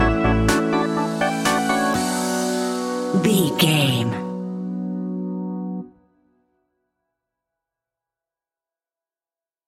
Aeolian/Minor
groovy
hypnotic
uplifting
synthesiser
drum machine
electric guitar
funky house
nu disco
upbeat
instrumentals
funky guitar
synth bass